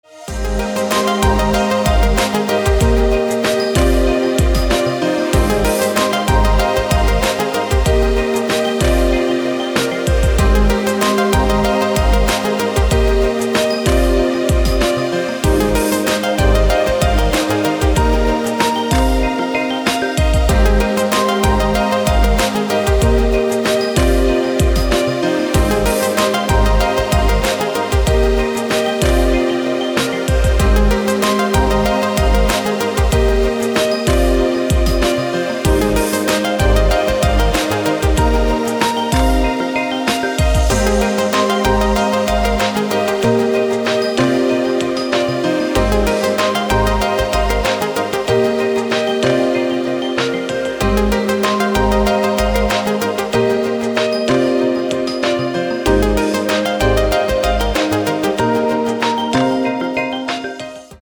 • Качество: 256, Stereo
красивые
спокойные
без слов
Electronica
Downtempo
пианино